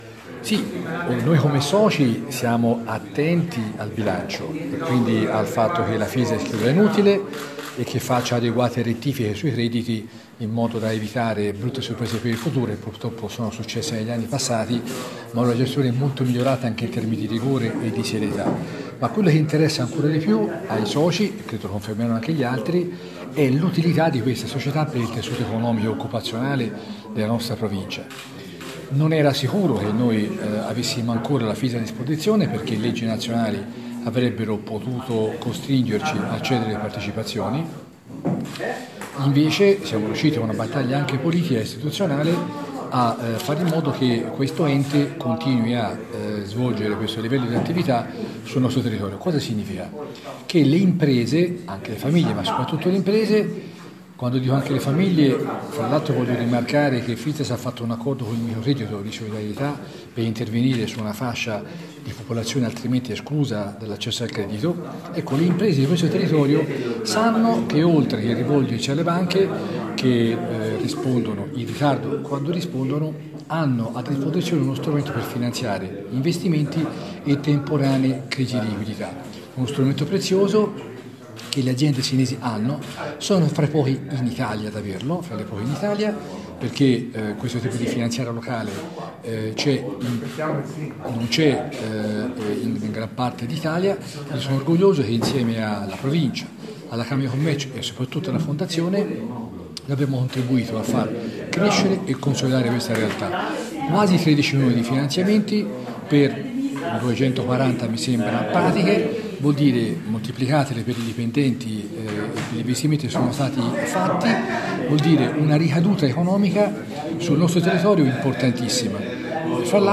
Interviste
Il sindaco Bruno Valentini